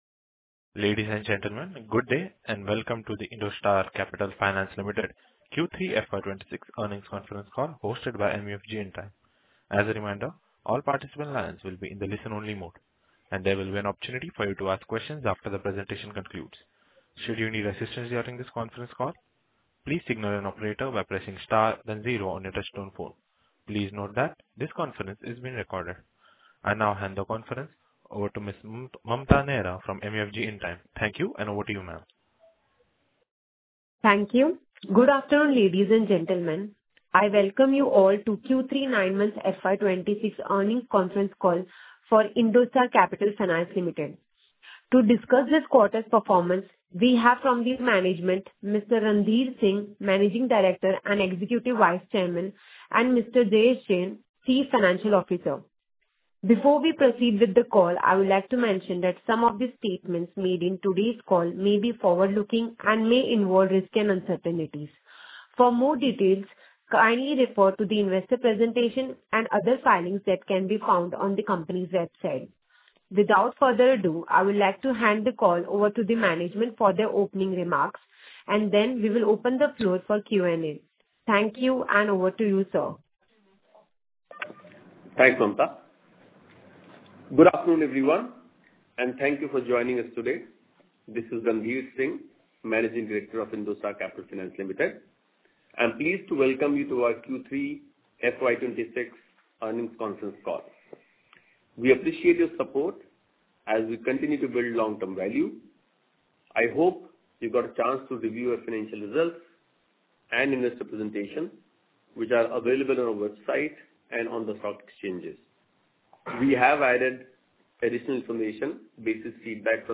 Q3FY26-Investor-Call-Audio-Recording.mp3